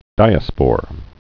(dīə-spôr)